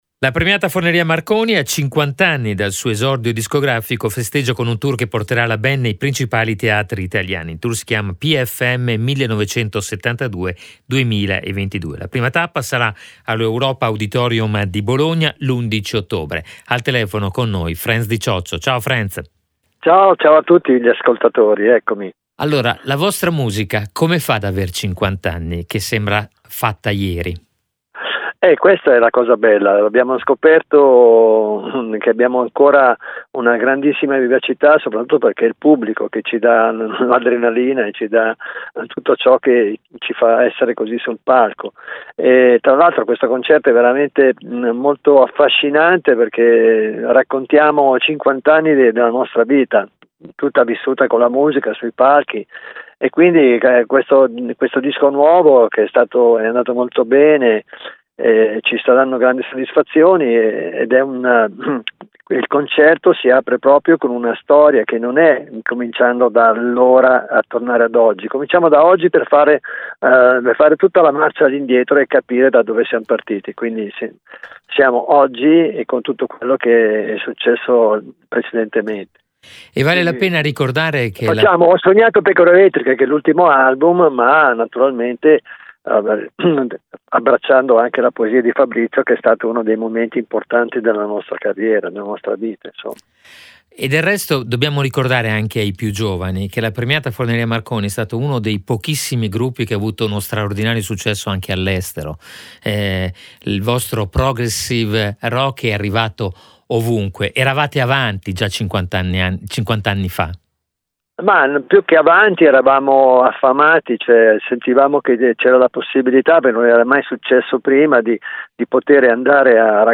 I 50 anni della Premiata Forneria Marconi: intervista Franz Di Cioccio